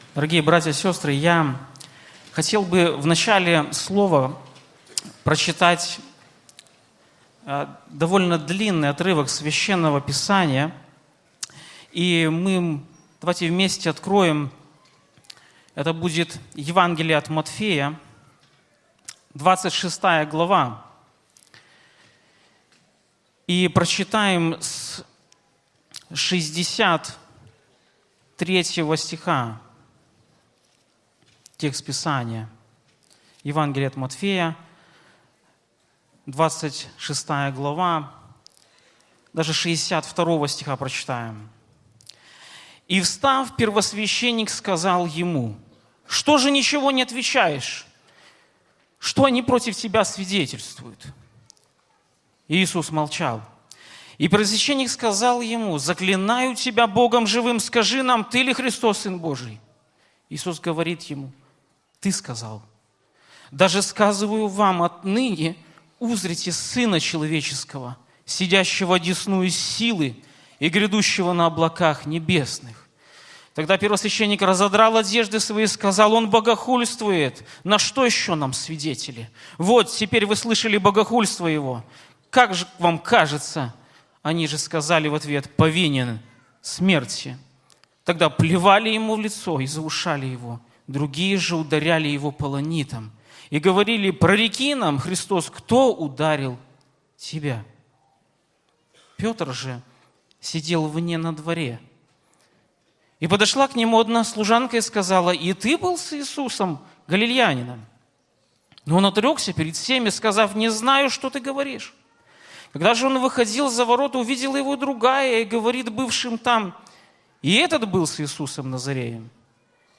Church4u - Проповеди
sermons_0004.mp3